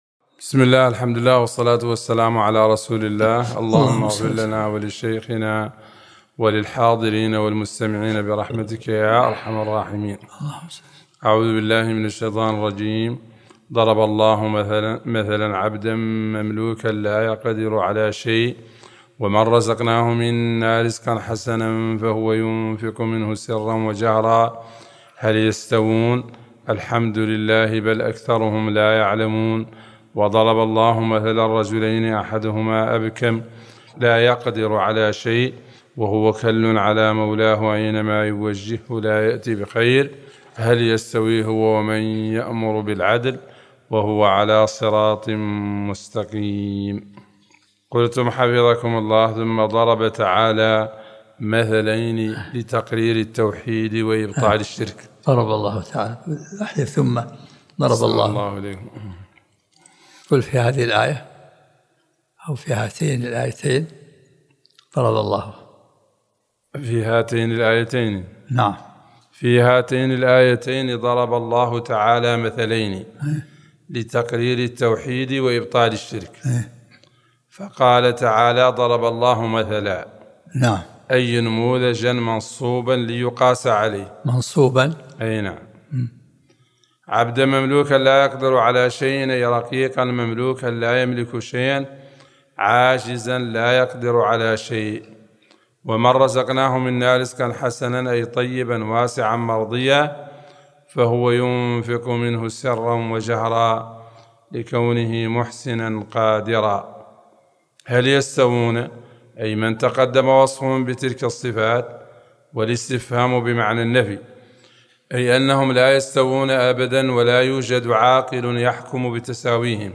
الدرس الثامن من سورة النحل